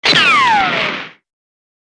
Ricochet Efeito Sonoro: Soundboard Botão
Ricochet Botão de Som